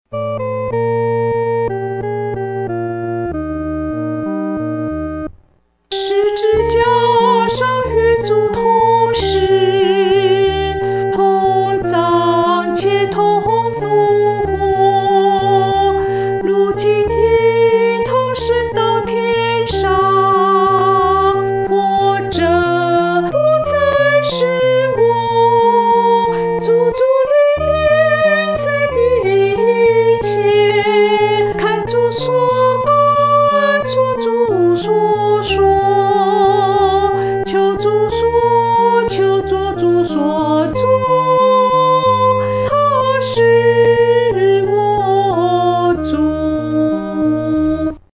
独唱（女第一声）